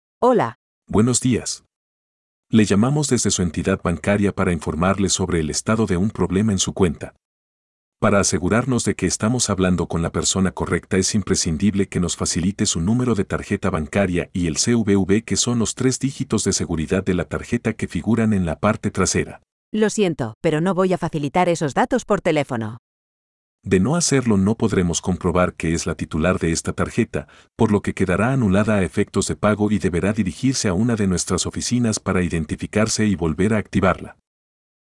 Pocos días después, se produce esta llamada telefónica:
llamada_banco.mp3